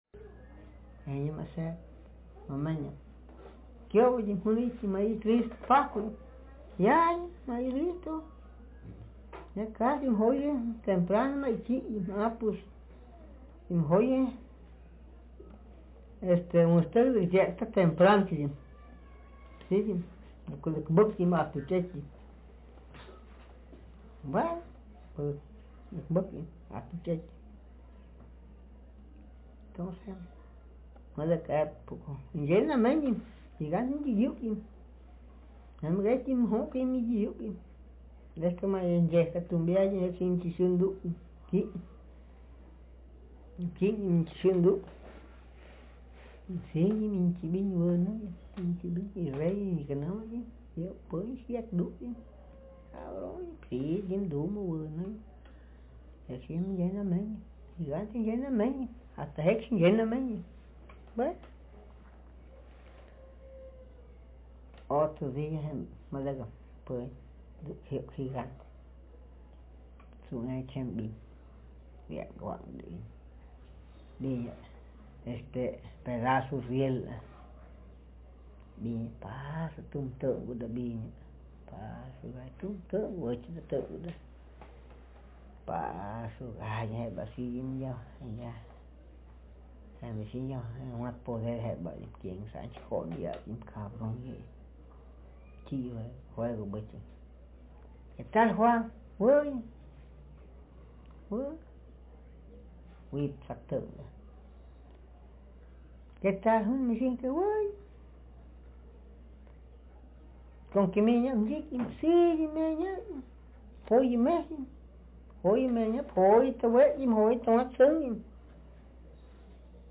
Speaker sex m
traditional narrative